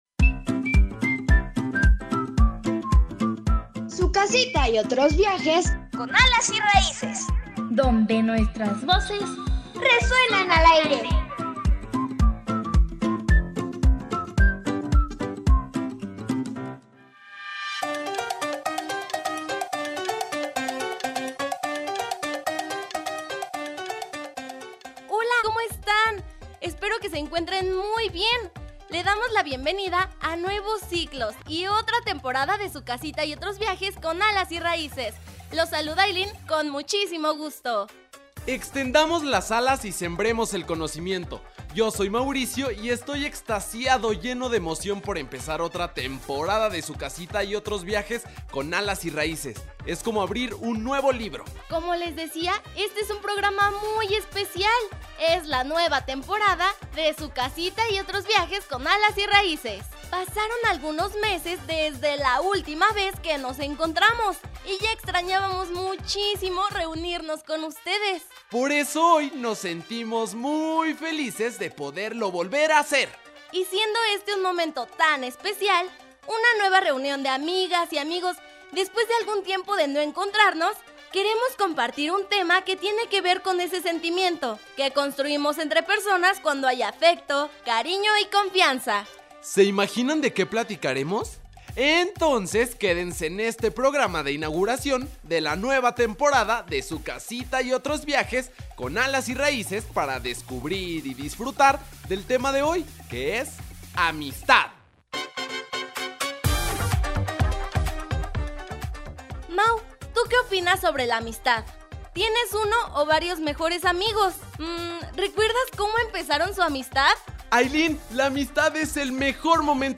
Género : Infantil